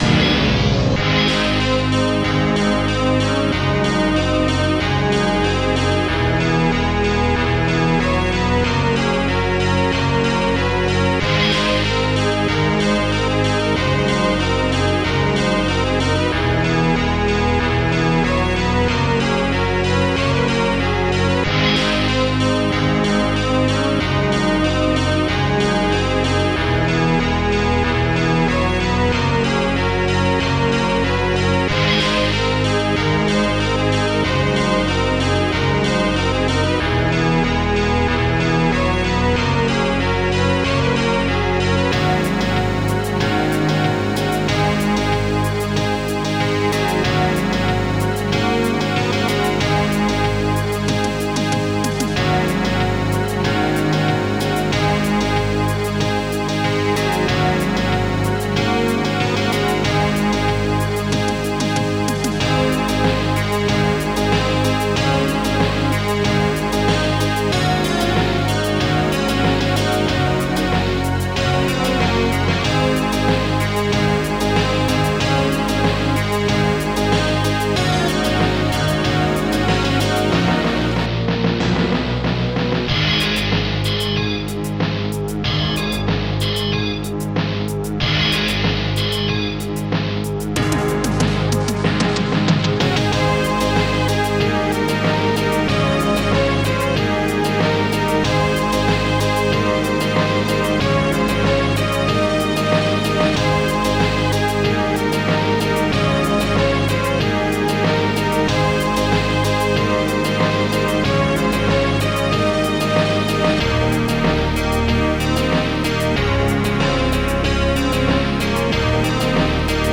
Digital Symphony Module